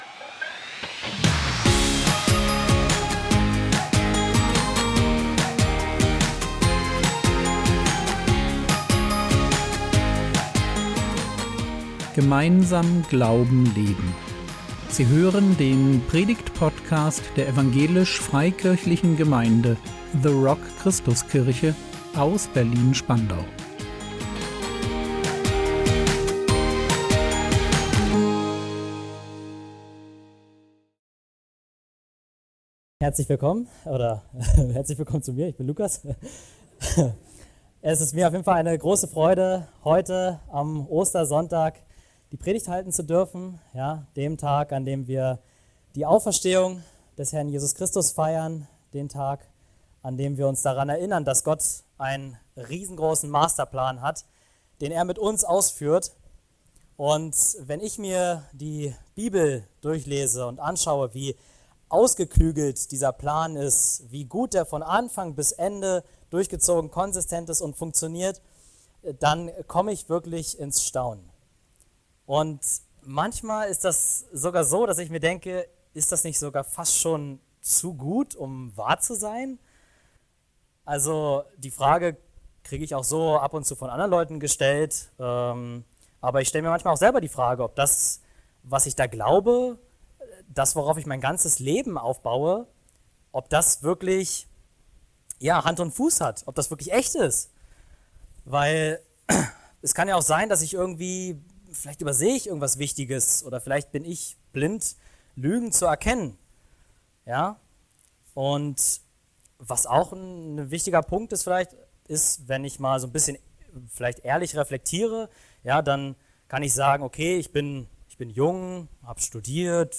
Psalm 22 - ein Kreuzpsalm? | 20.04.2025 ~ Predigt Podcast der EFG The Rock Christuskirche Berlin Podcast